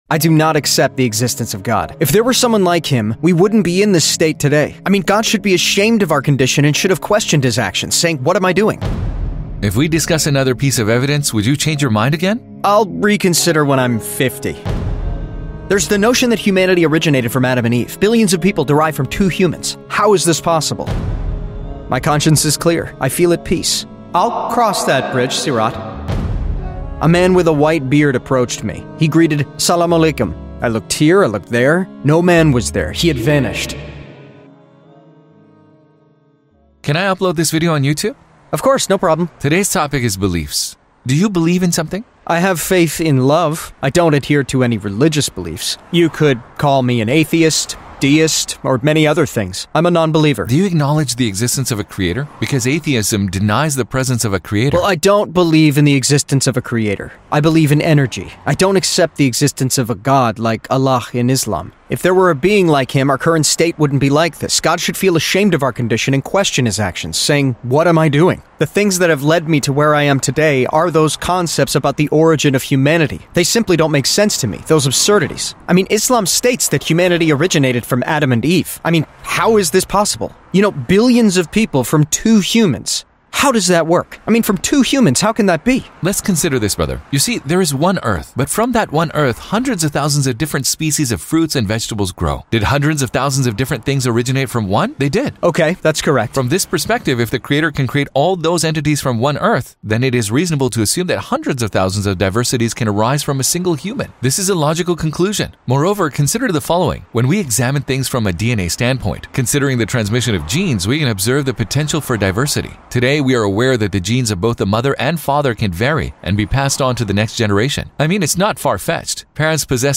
50-Year-Old Atheist VS Young Muslim! - Atheist Tells Mystical Event He Saw!.mp3